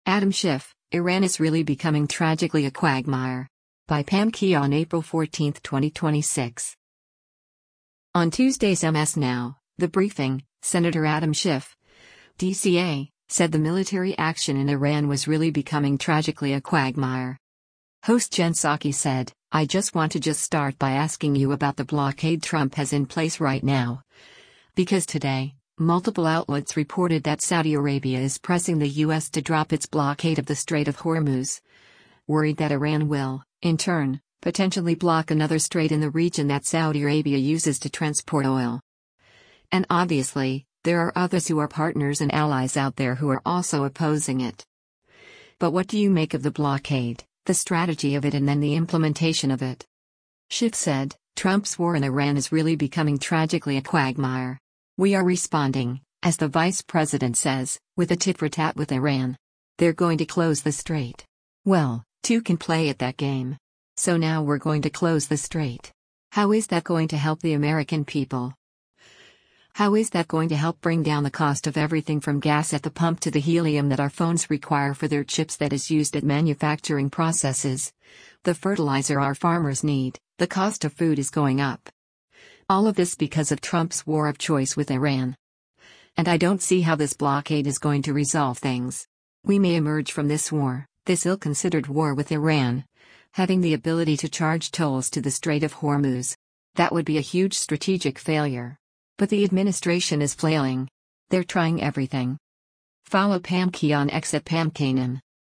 On Tuesday’s MS NOW, “The Briefing,” Sen. Adam Schiff (D-CA) said the military action in Iran was “really becoming tragically a quagmire.”